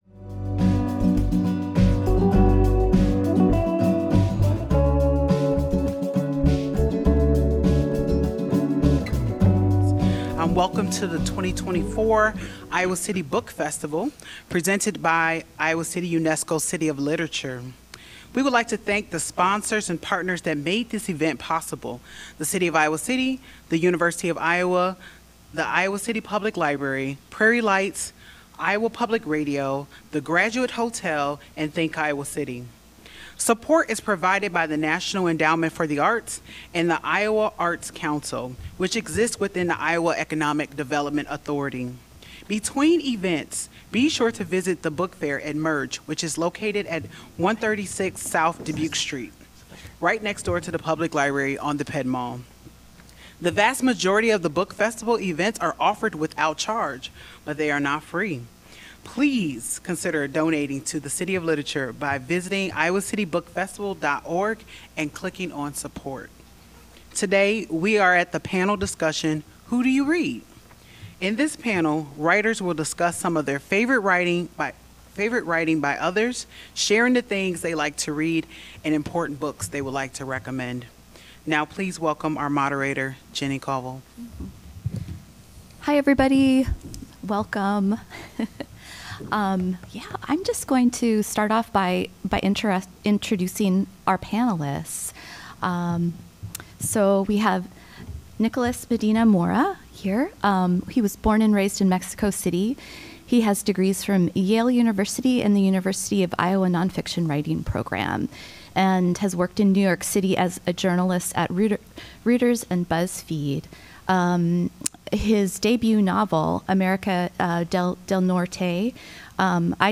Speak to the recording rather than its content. Iowa City UNESCO City of Literature presents a panel discussion from its 2024 Iowa City Book Festival. A panel of writers are asked:What authors do you love to read, in your own or other literatures?